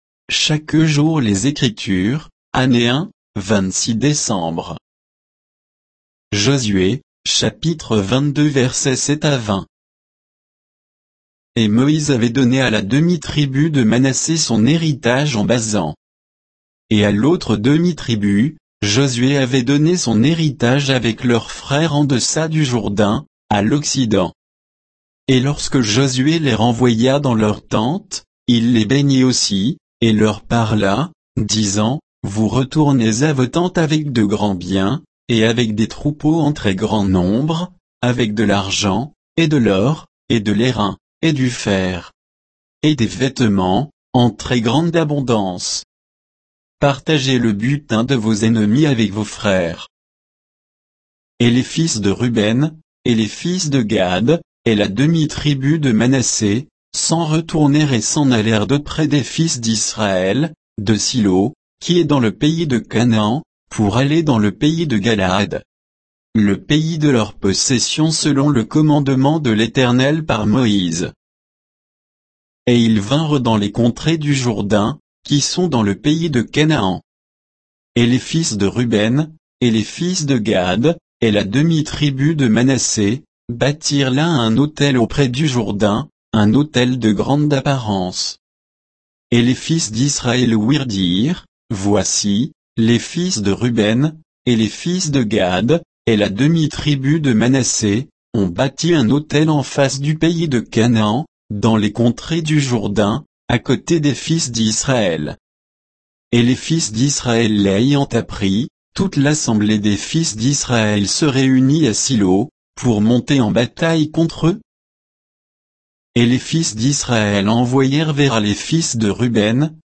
Méditation quoditienne de Chaque jour les Écritures sur Josué 22, 7 à 20